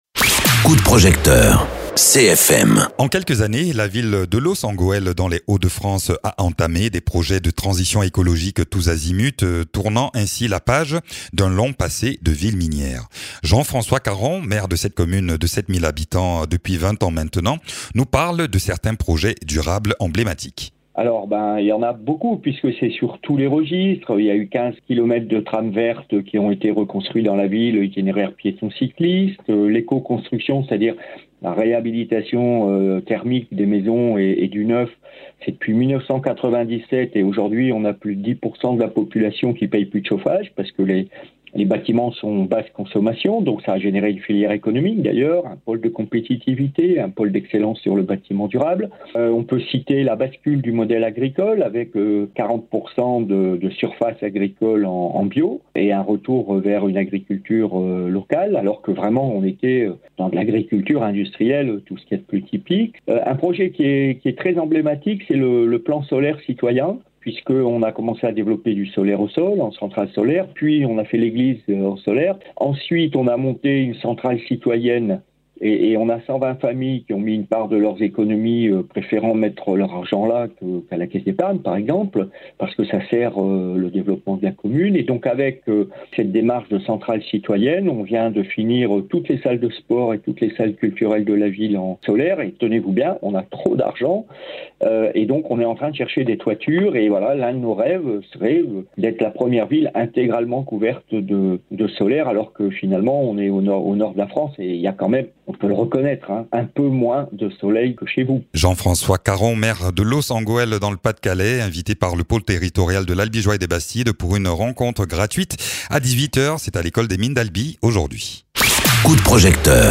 Interviews
Invité(s) : Jean-François Caron, maire de Loos-en-Gohelle.